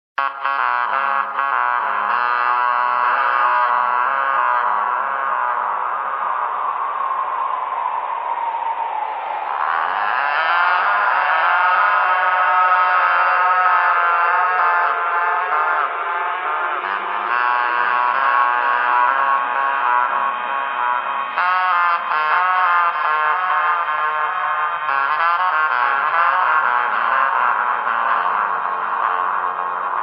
P O L I C E